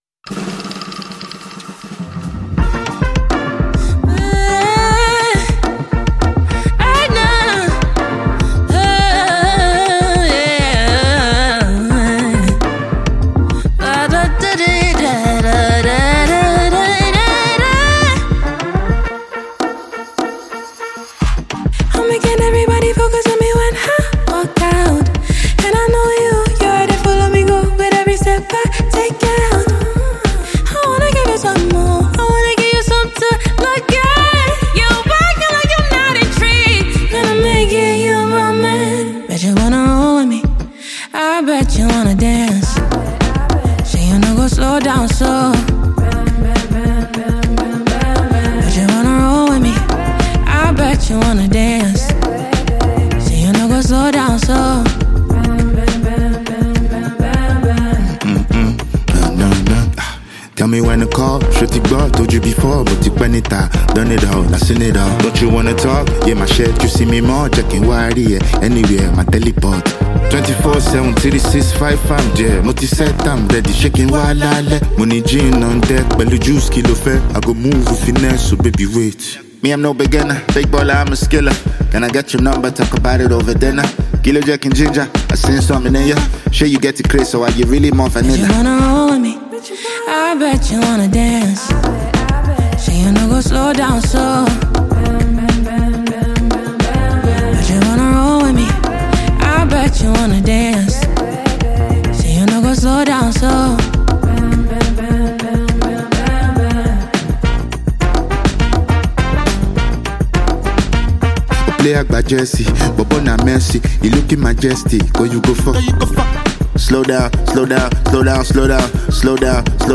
Nigerian rapper and singer
offering a mix of Afrobeat, hip-hop, and highlife sounds.